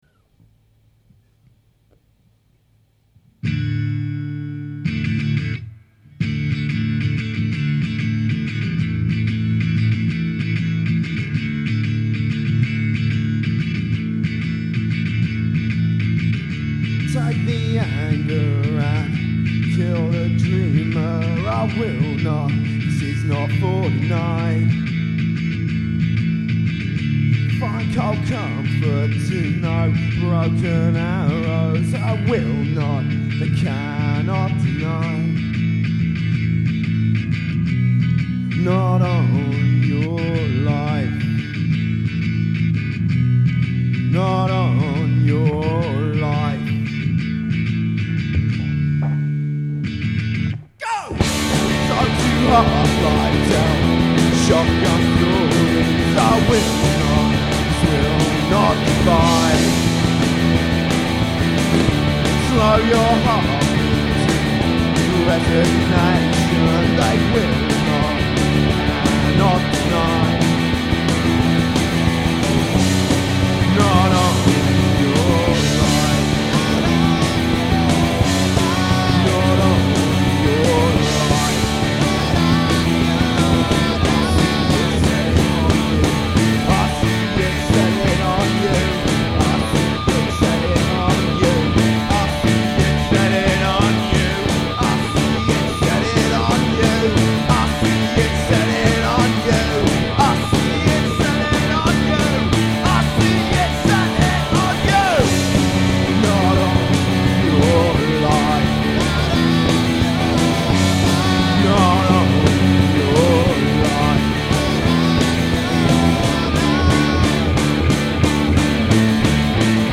1-5 diy recordings mushroom rehearsal studios